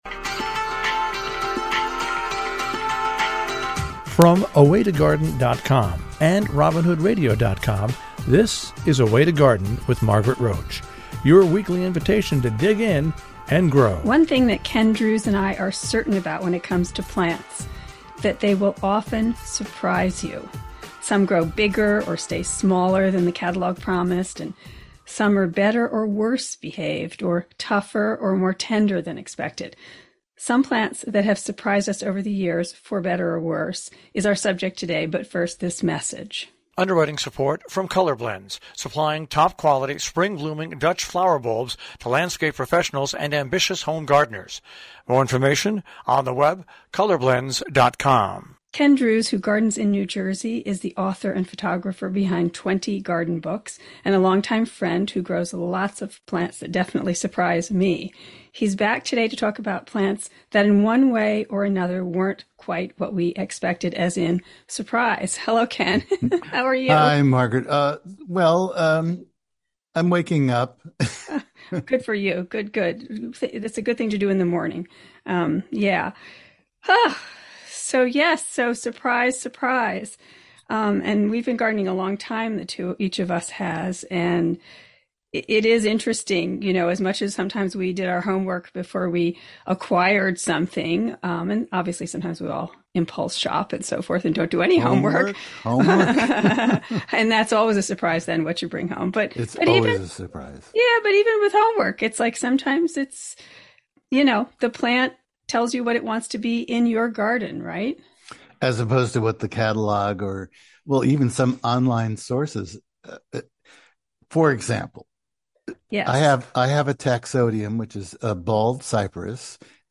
Some grow bigger or stay smaller than the catalog promised, and some are better- or worse-behaved, or tougher or more tender than expected. Some plants that have surprised us over the years, for better or worse, was the subject of a recent conversation we had.